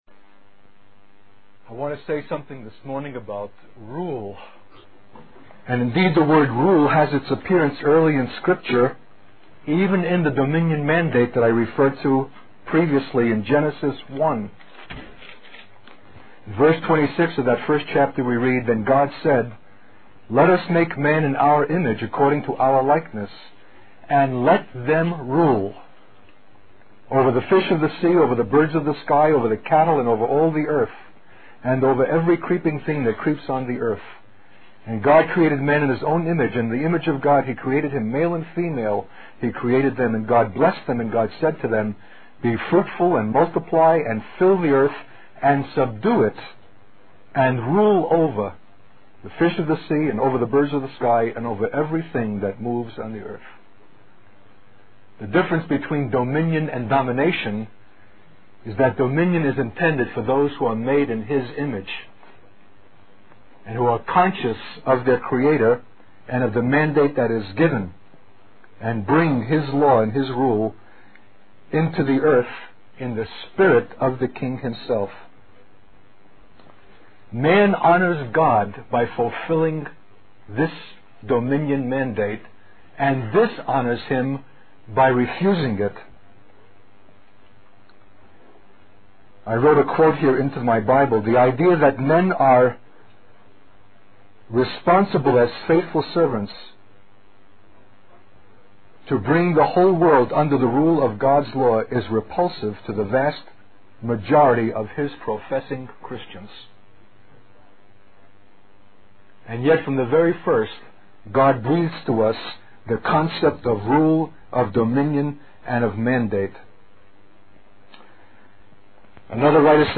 In this sermon, the preacher focuses on the story of Joshua and the city of Jericho.